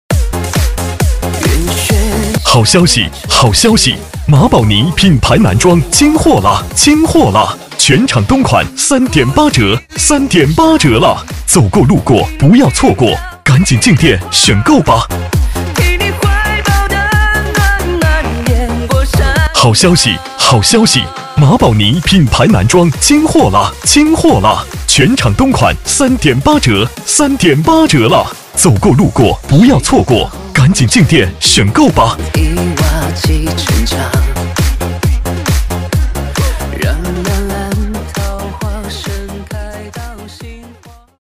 【男60号促销】马堡尼品牌男装清货.mp3